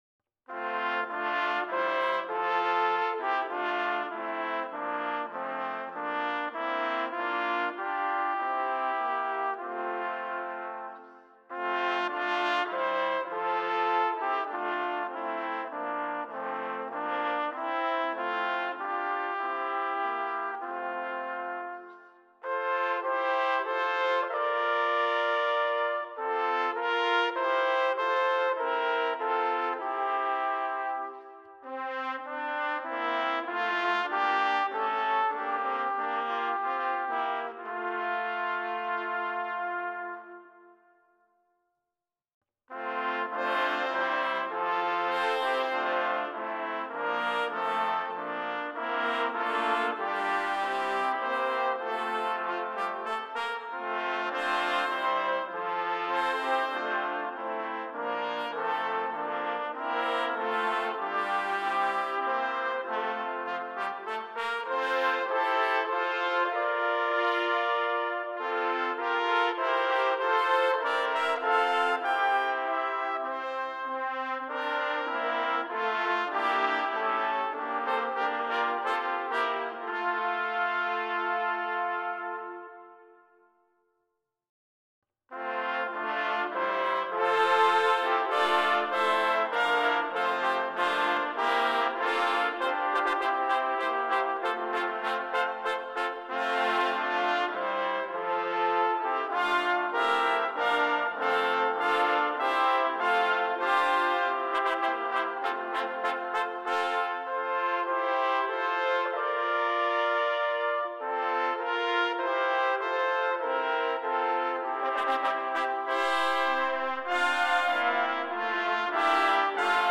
Number of Trumpets: 3
Exhilarating, majestic, and inspiring.